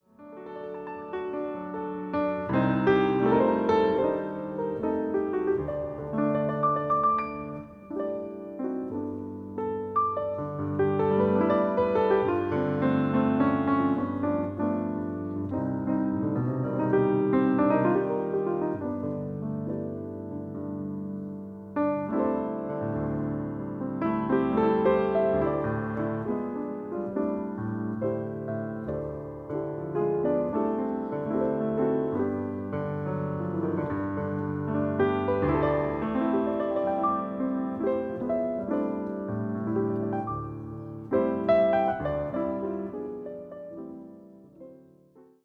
ピアノ